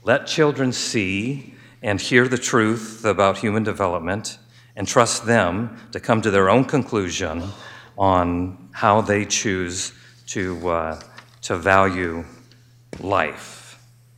Sen. Greg Blanc, a Republican from Rapid City says it a science-based curriculum add-on.